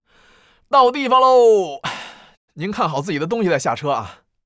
序章与第一章配音资产
c01_9车夫_19.wav